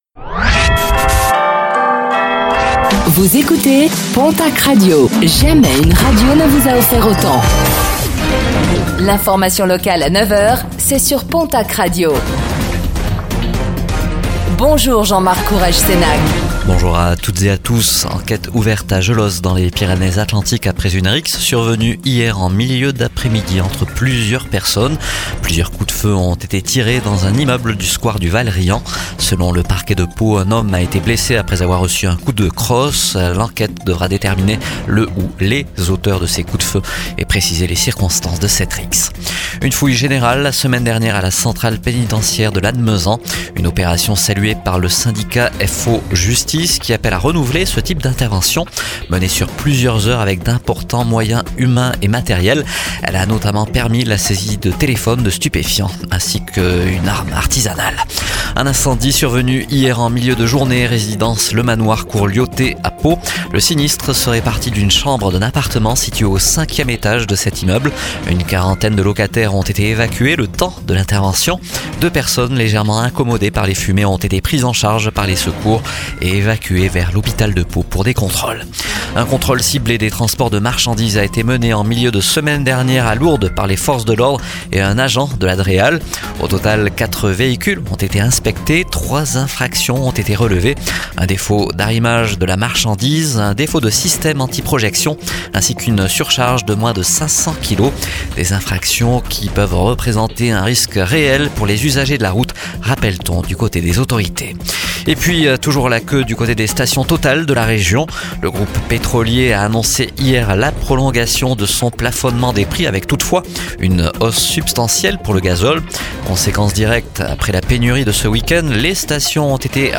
Réécoutez le flash d'information locale de ce mercredi 08 avril 2026